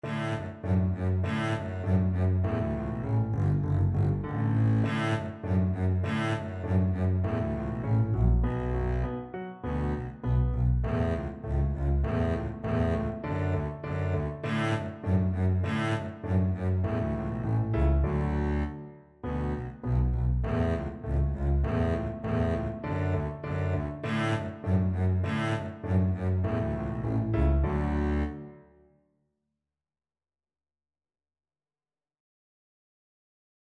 Double Bass version
Allegretto
2/4 (View more 2/4 Music)
F#2-A3
Classical (View more Classical Double Bass Music)